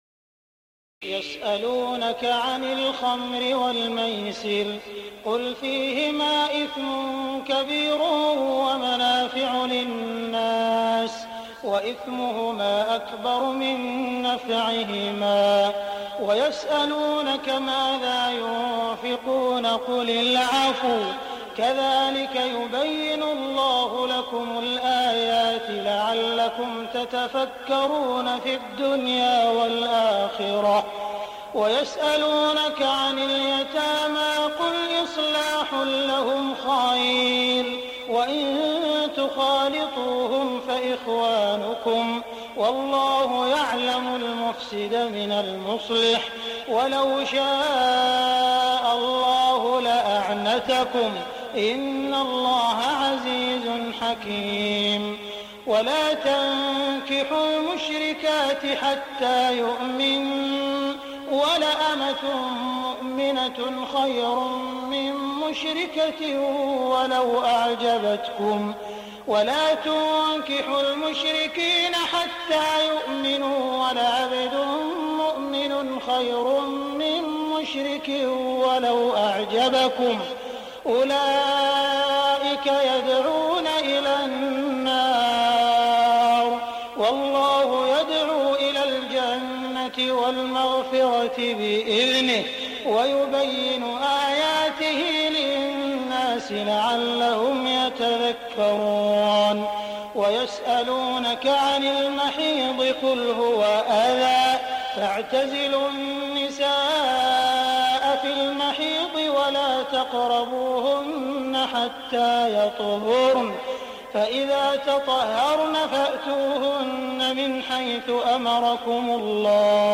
تهجد ليلة 22 رمضان 1418هـ من سورة البقرة (219-253) Tahajjud 22 st night Ramadan 1418H from Surah Al-Baqara > تراويح الحرم المكي عام 1418 🕋 > التراويح - تلاوات الحرمين